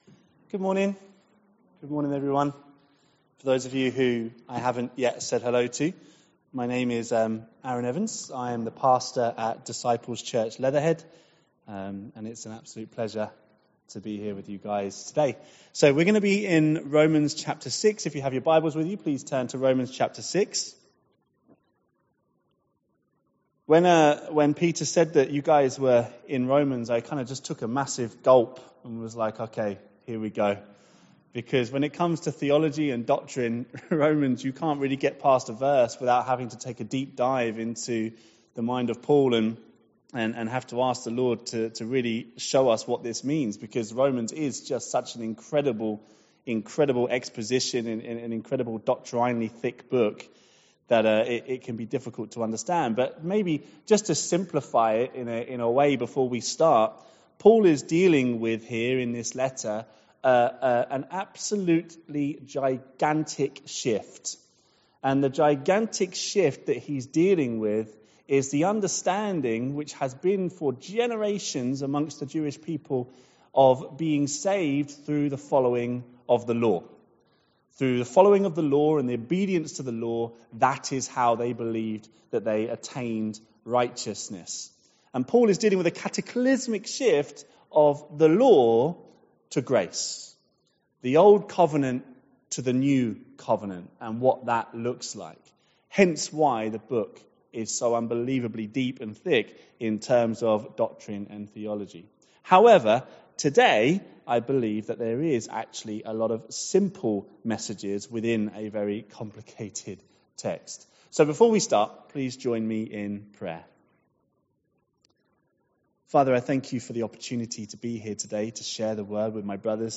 Series: Guest Speakers , Sunday morning studies Tagged with Becoming dead to sin